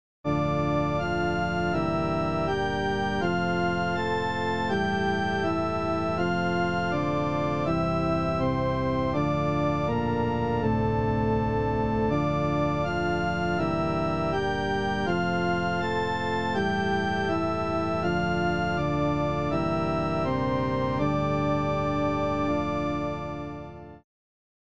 Ominous organ intro (265.4 kb
trial-intro.mp3